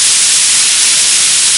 Noise.ogg